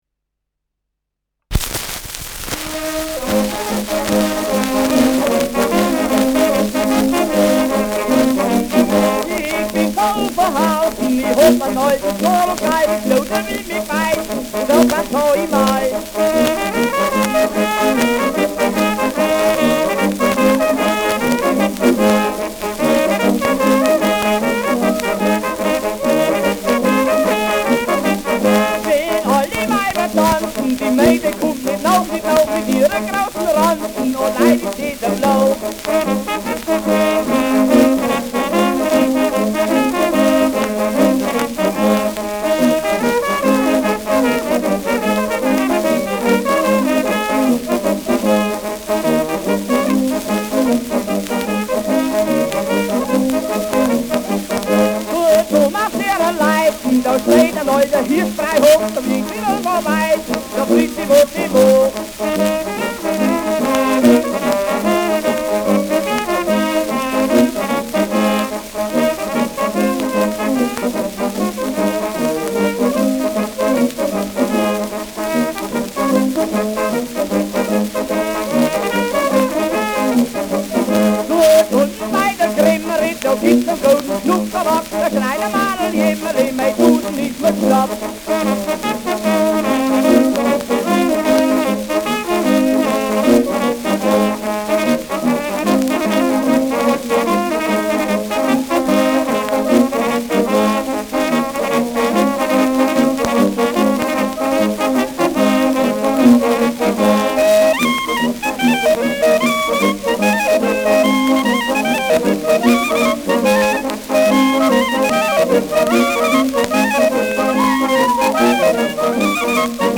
Schellackplatte
ausgeprägtes Rauschen
Mit Juchzern.
[Nürnberg] (Aufnahmeort)